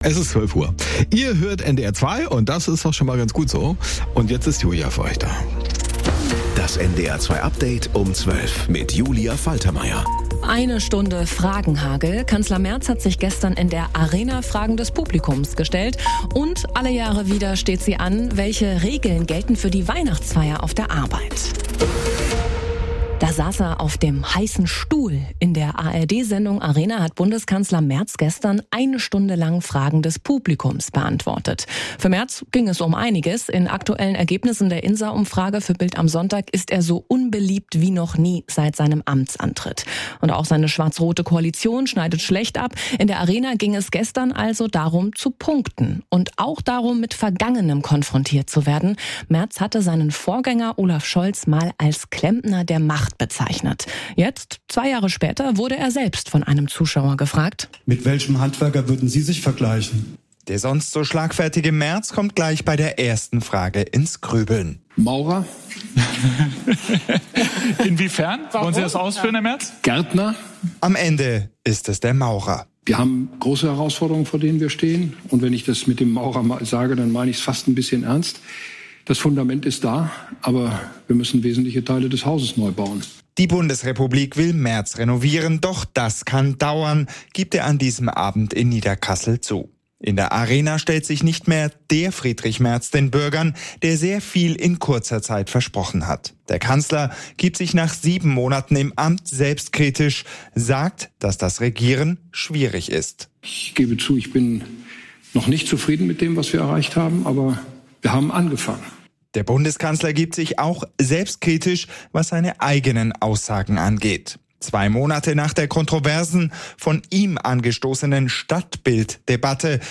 Mit unseren Korrespondent*innen und Reporter*innen, im Norden, in Deutschland und in der Welt.